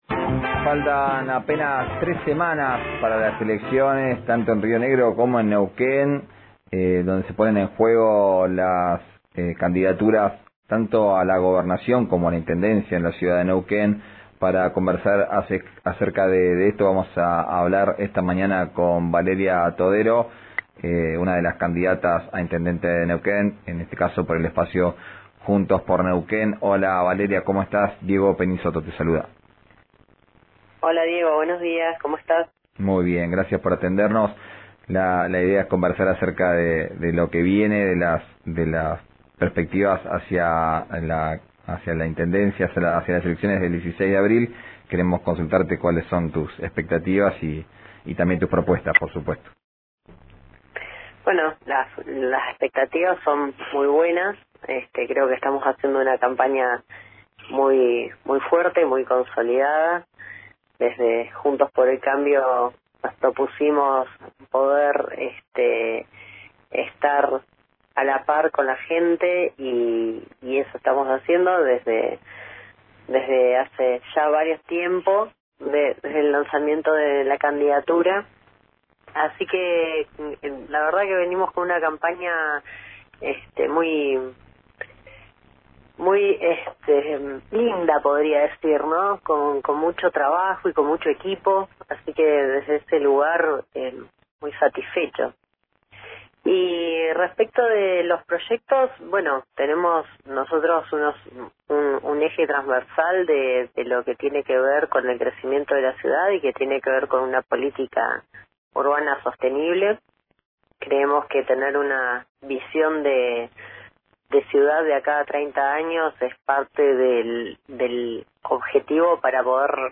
Esto fue lo que dijo en la nota con RN Radio.